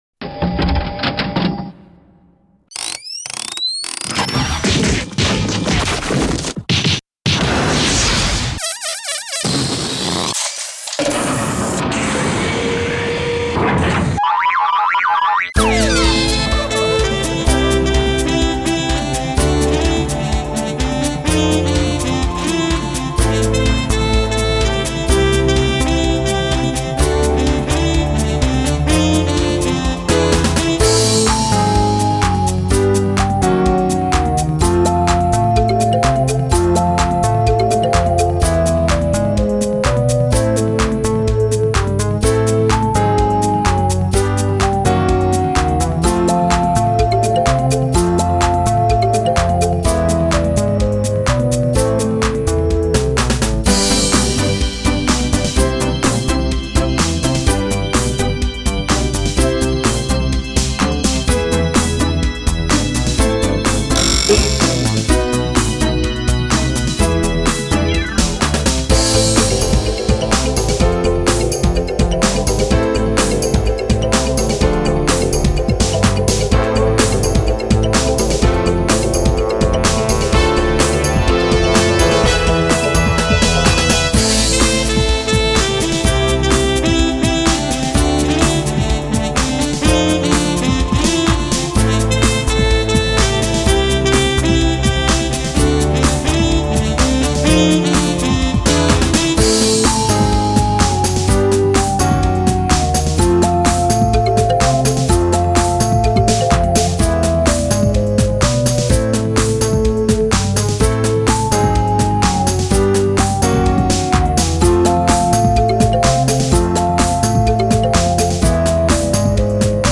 SchouderCom - Zonder zang
Lied-3-Flippergek-instrumentaal-.mp3